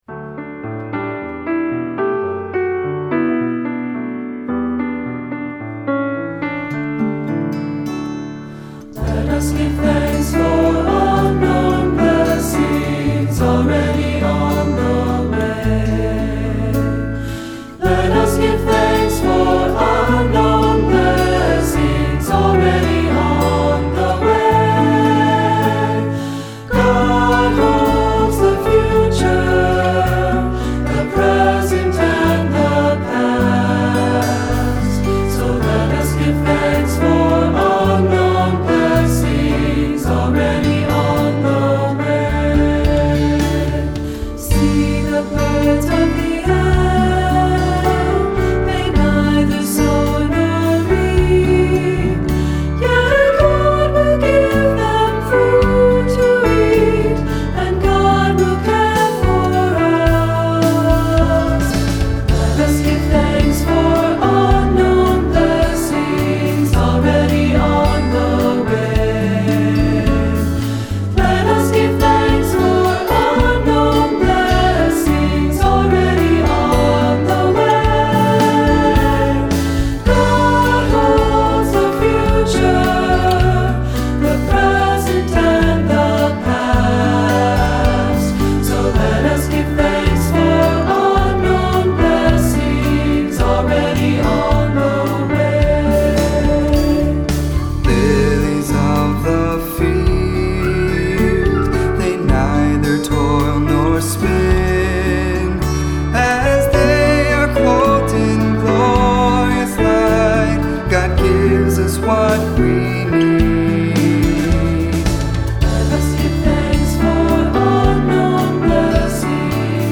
Voicing: Two-part mixed; Cantor; Assembly